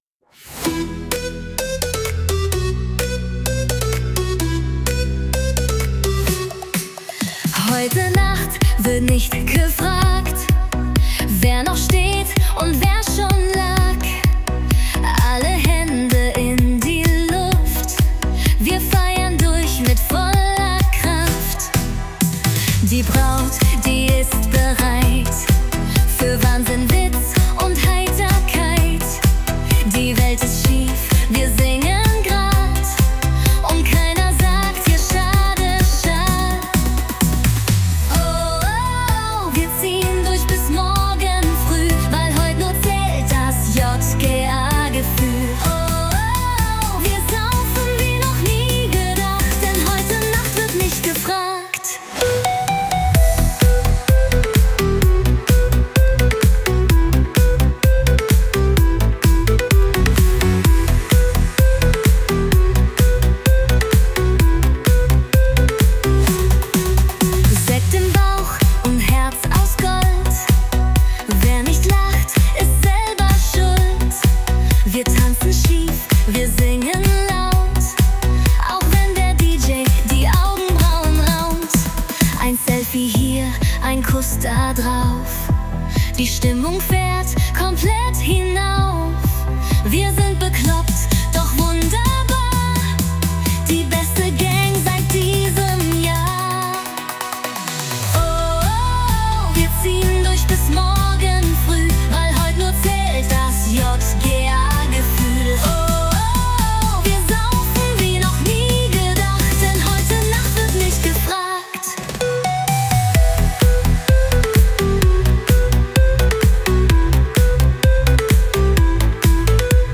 Jungesellinnen / Junggesellen Event: